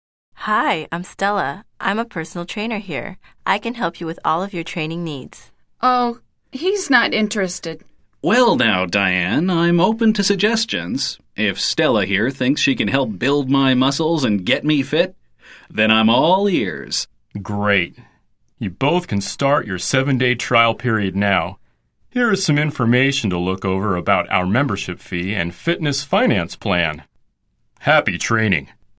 聽力大考驗：來聽老美怎麼說？